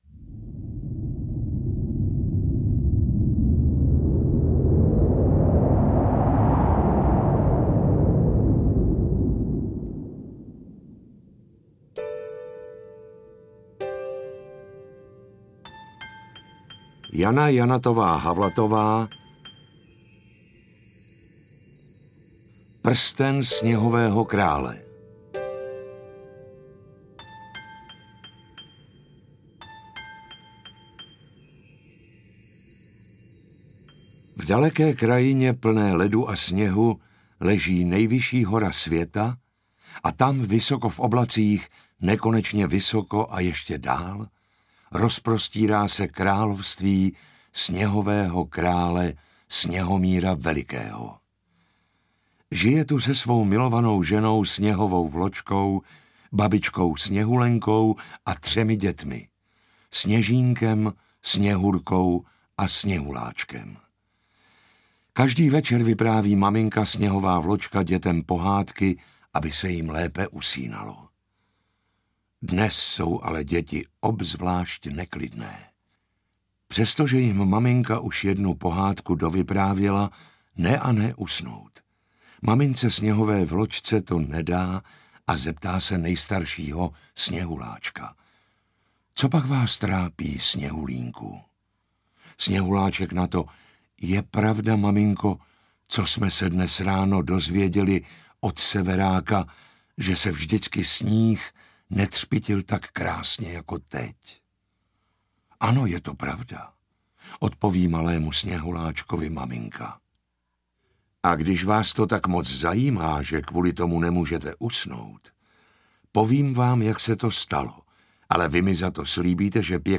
Sama autorka se zhostila v pohádkové dramatizaci role komorné Kláry, v roli charismatického...
AudioKniha ke stažení, 6 x mp3, délka 1 hod. 4 min., velikost 58,3 MB, česky